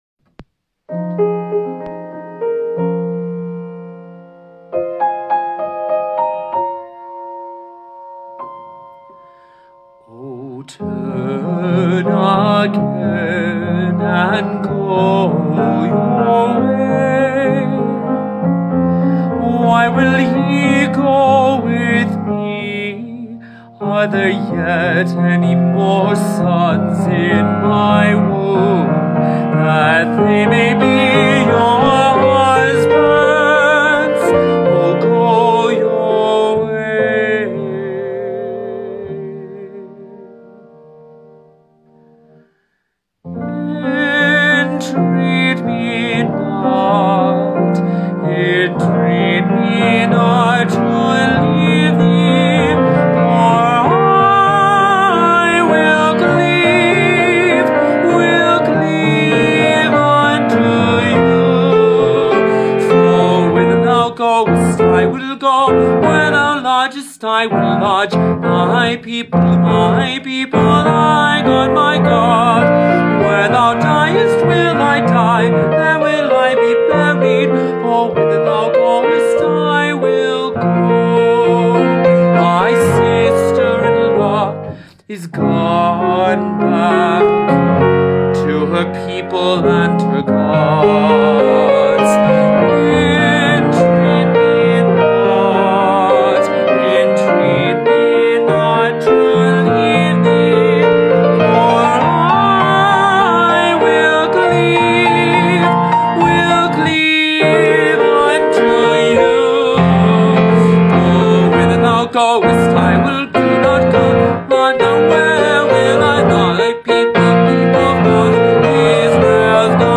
Vocal Duet and Piano; Based on Ruth 1:16-17